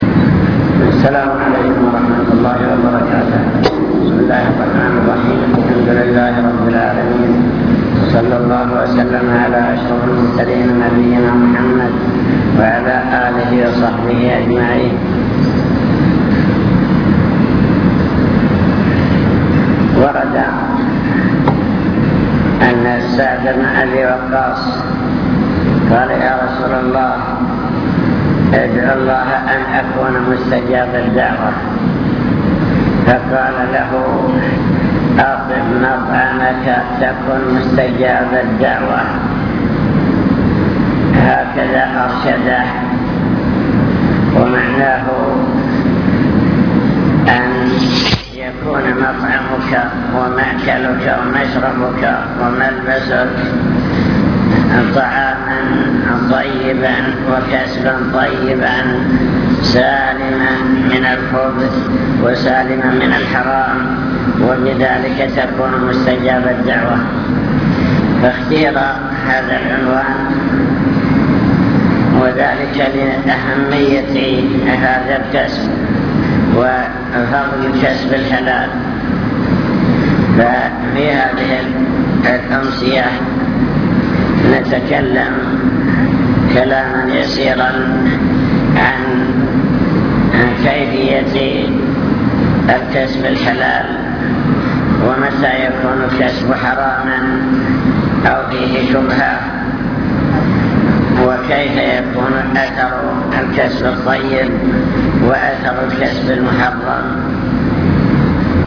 المكتبة الصوتية  تسجيلات - محاضرات ودروس  أطب مطعمك